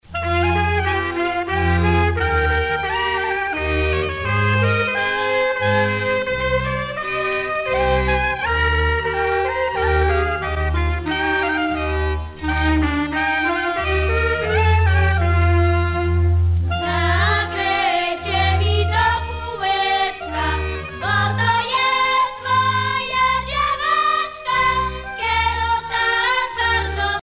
orchestre
Pièce musicale inédite